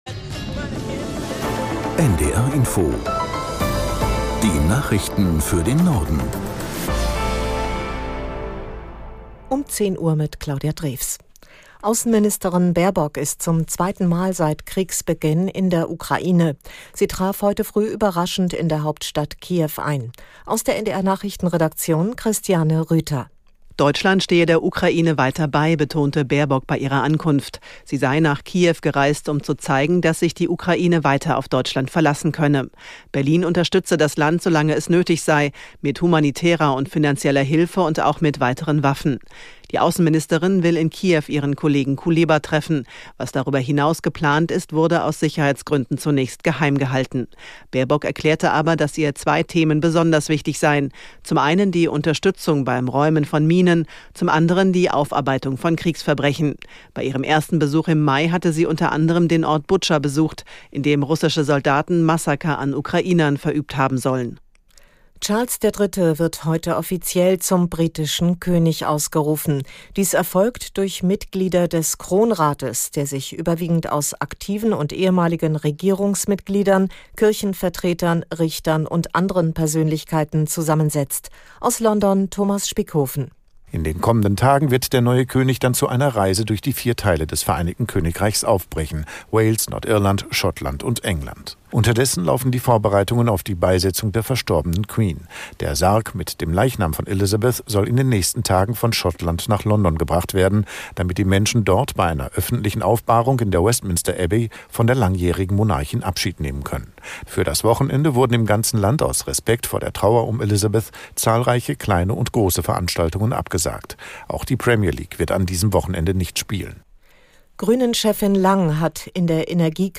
Nachrichten - 10.09.2022